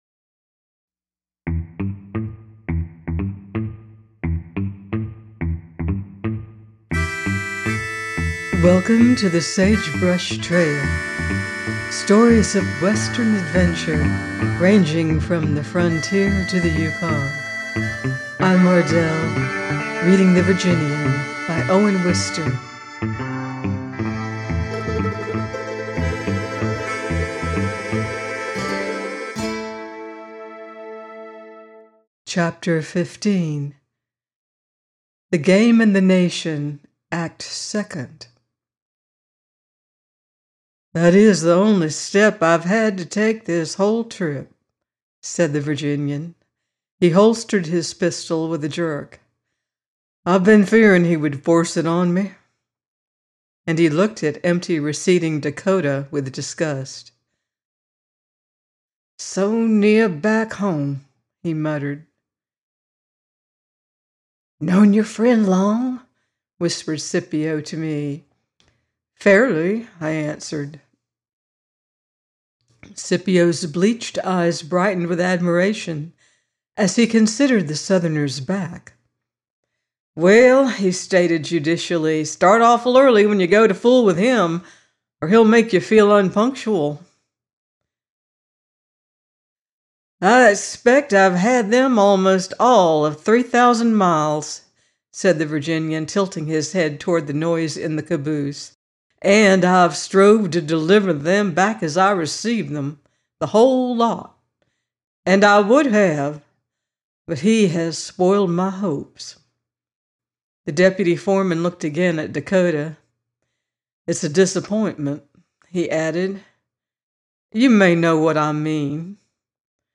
The Virginian 15 - by Owen Wister - audiobook